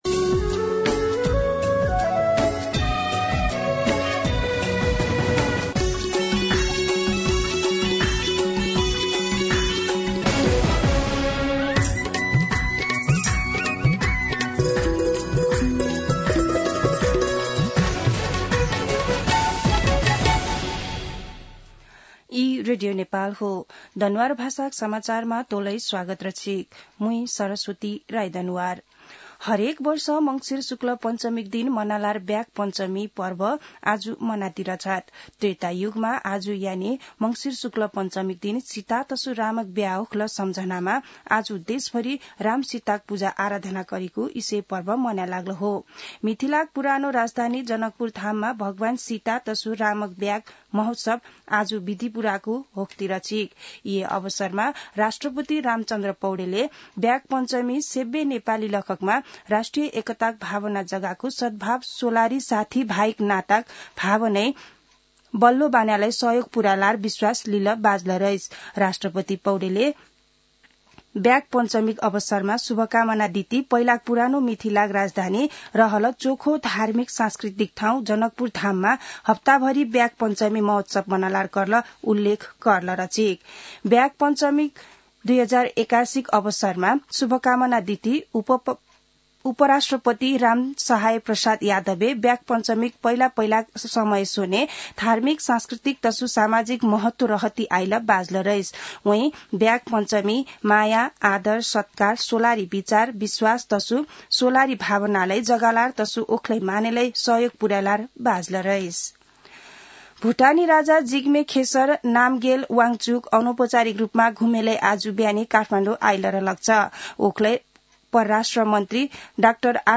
दनुवार भाषामा समाचार : २२ मंसिर , २०८१